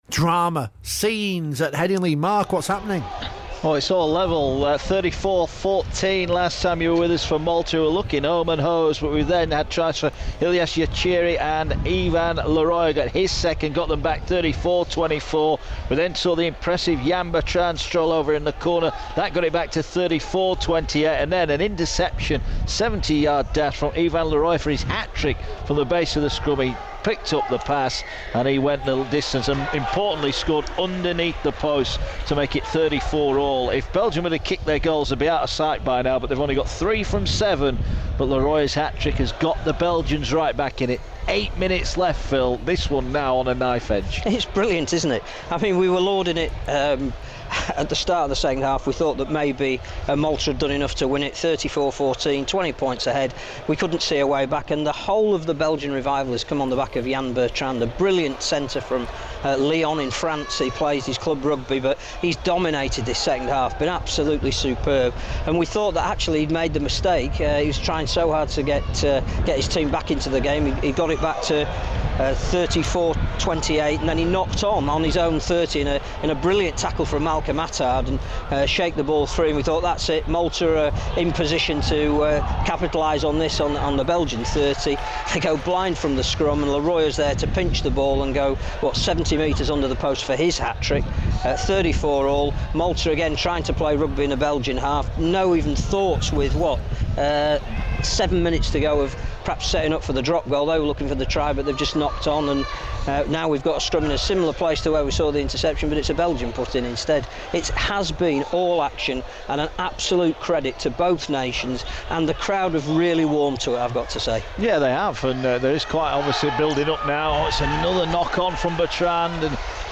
report on the final stages of an enthralling international clash between Malta and Belgium at Headingley, Leeds.